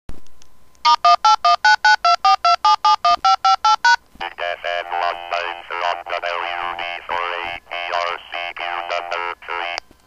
APRStt uses DTMF for sending callsigns and grid square position reports and the spacecraft acknowledges the uplinks by voice response.
Here are some of the Voice Responses to DTMF uplinks: